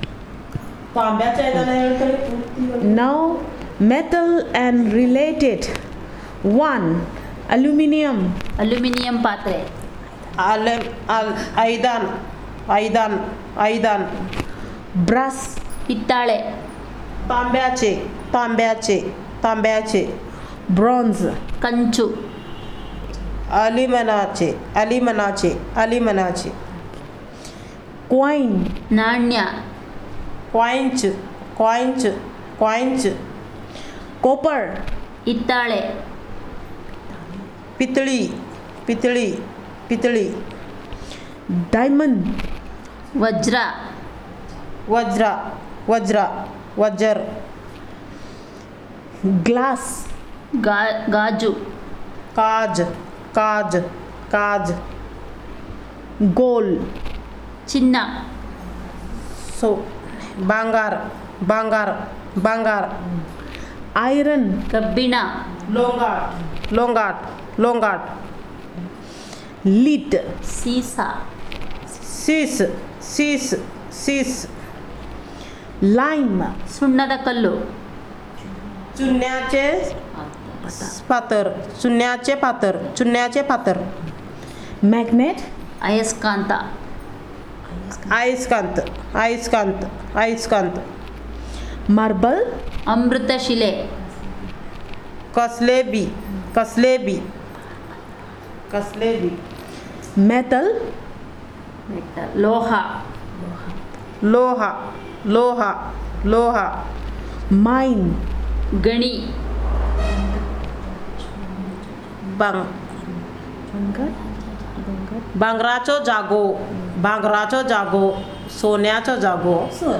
Elicitation of words about metal and related